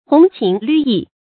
紅情綠意 注音： ㄏㄨㄥˊ ㄑㄧㄥˊ ㄌㄩˋ ㄧˋ 讀音讀法： 意思解釋： 形容艷麗的春天景色。